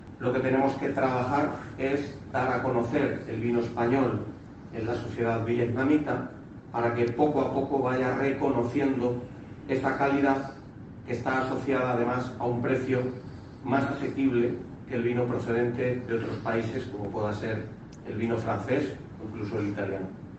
El presidente de la Diputación destaca las potencialidades de nuestros vinos y de FENAVIN en un encuentro con una veintena de medios de comunicación en Hanói